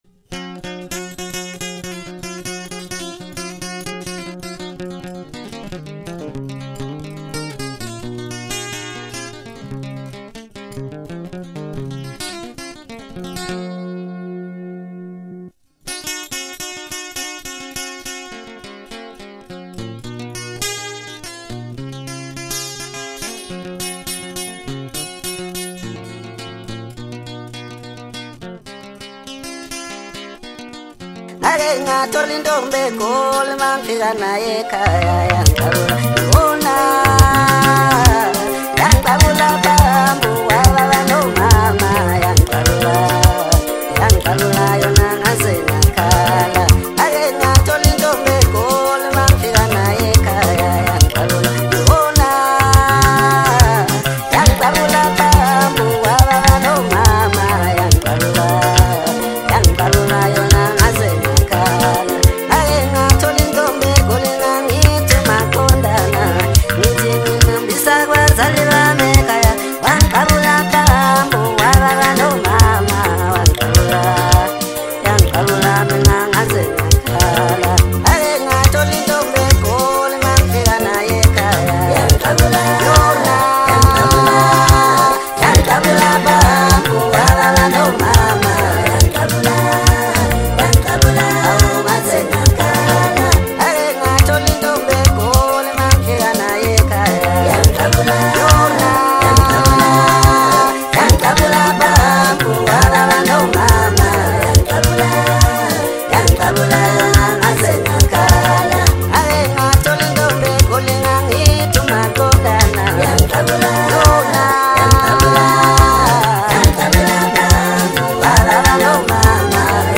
Home » Maskandi